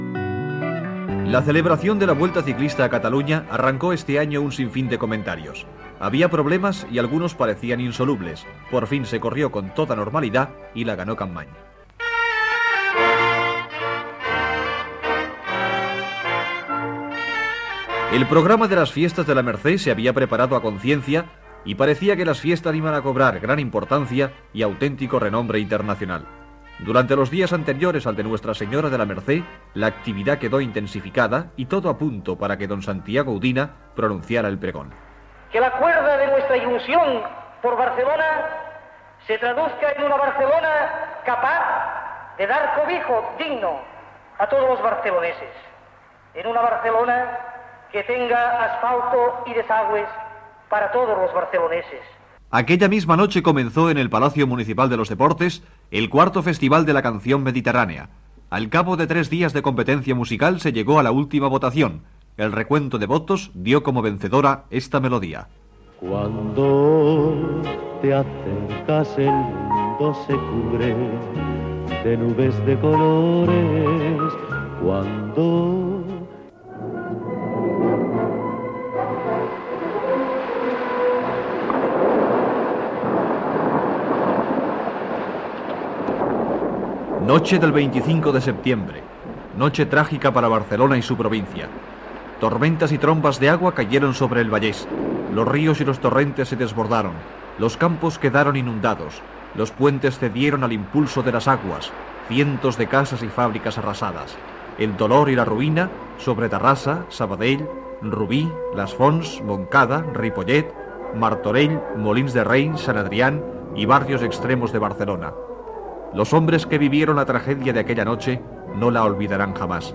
Informatiu